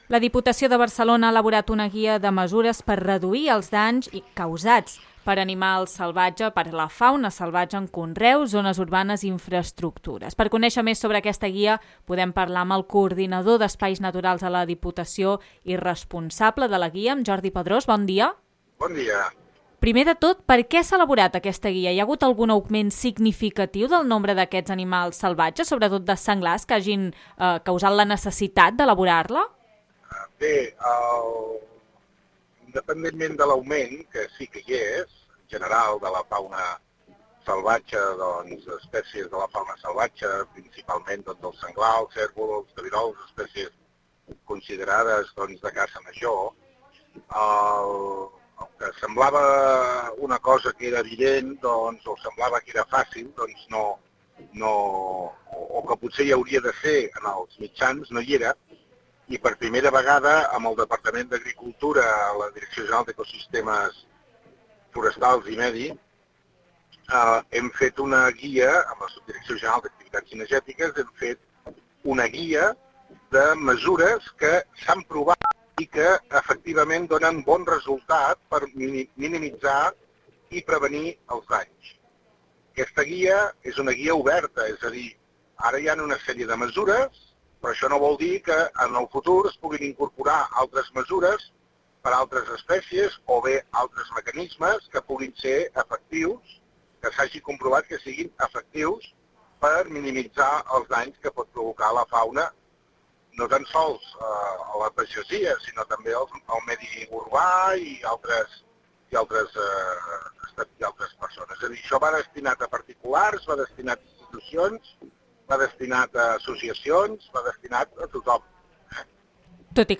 En una entrevista